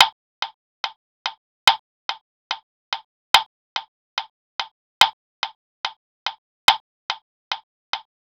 HERE'S A 10 FRAME CLICK TRACK (1.5mb)
10frameclicktrack.aif